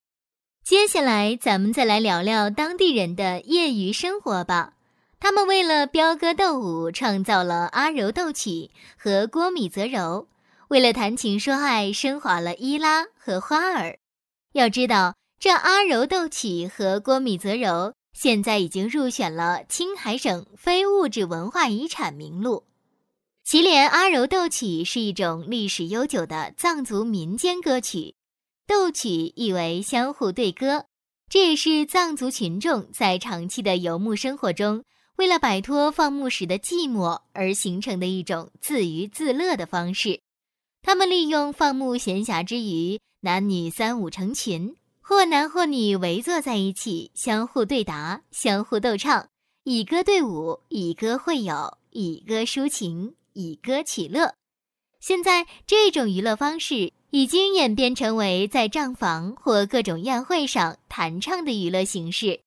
女218-导游词解说
女218-温柔甜美 素人自然